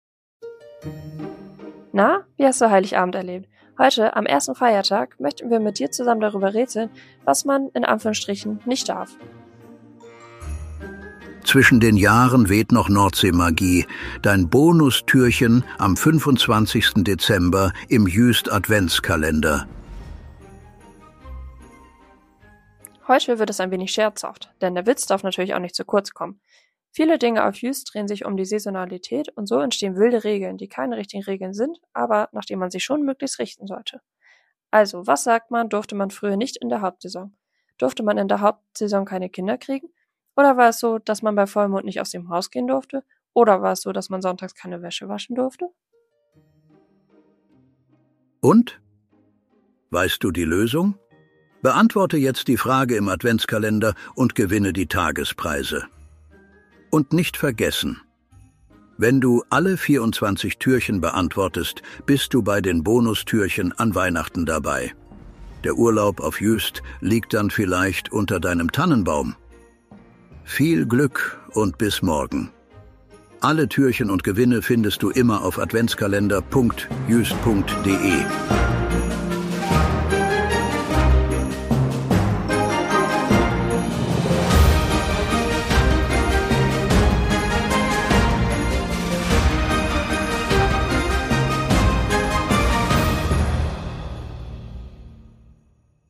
guten Geistern der Insel Juist, die sich am Mikro abwechseln und